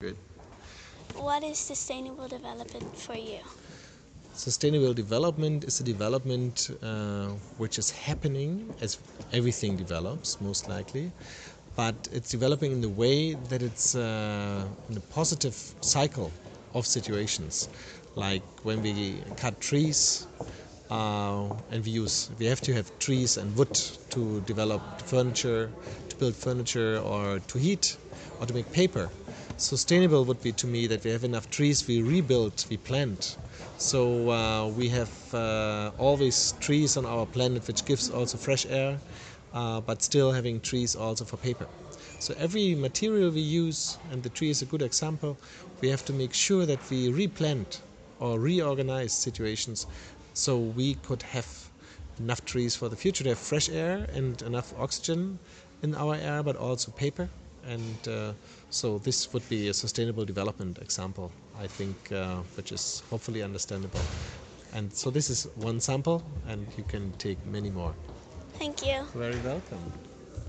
Recorded in Riga, 9 January 2015